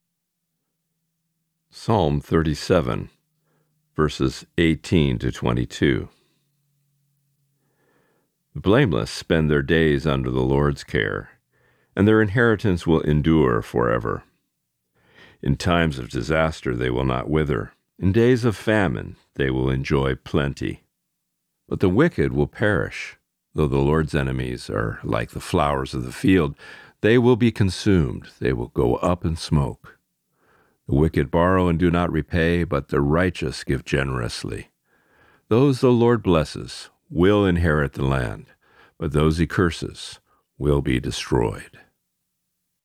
Reading: Psalm 37:18-22